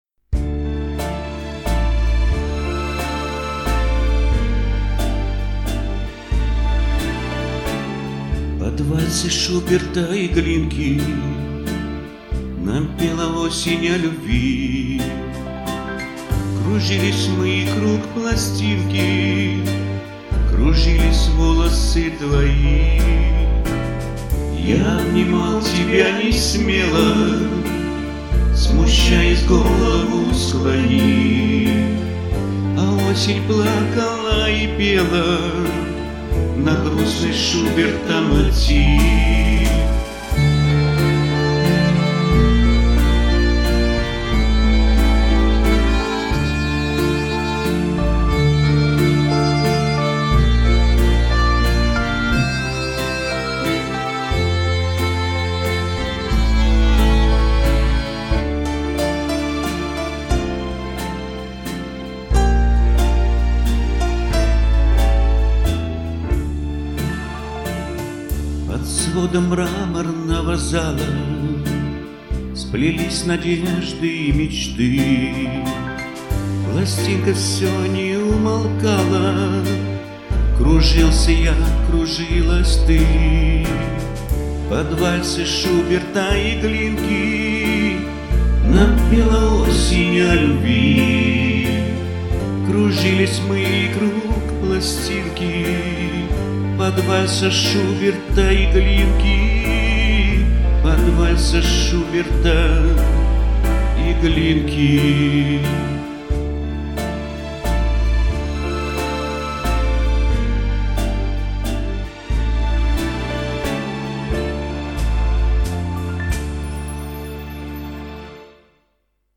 В КОМПОЗИЦИИ ИСПОЛЬЗОВАН ФРАГМЕНТ МУЗЫКИ ФРАНЦА ШУБЕРТА.